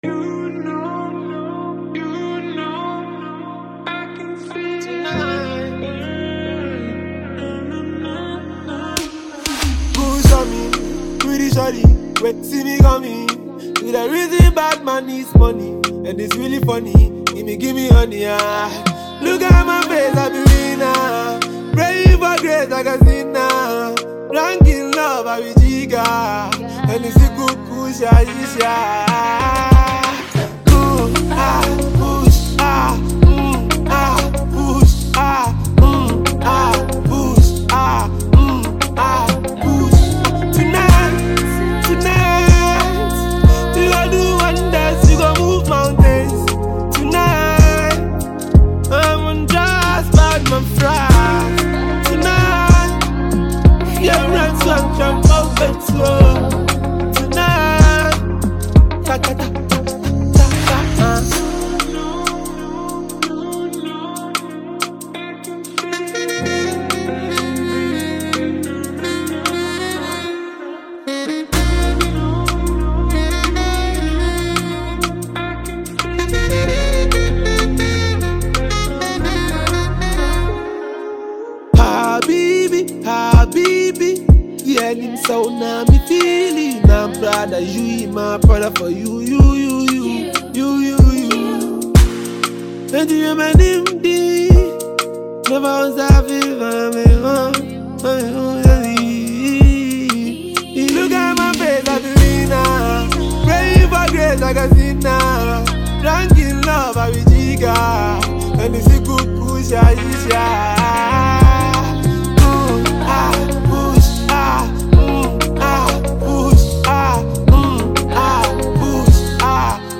a Ghanaian trapper